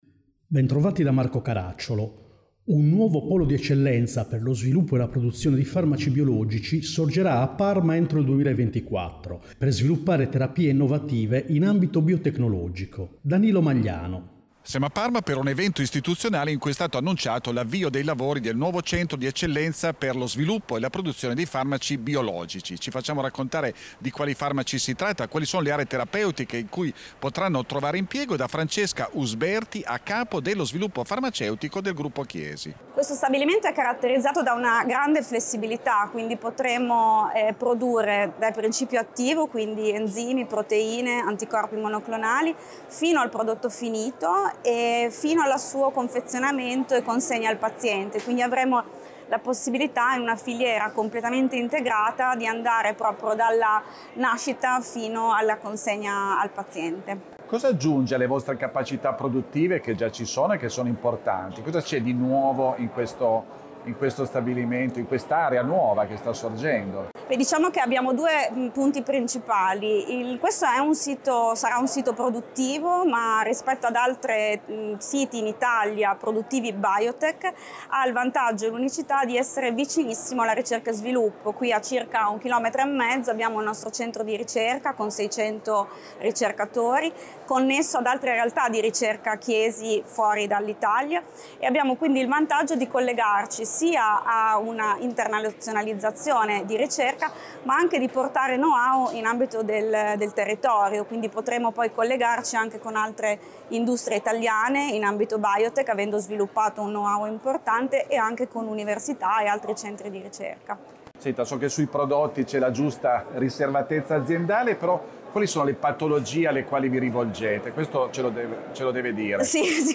Puntata con sigla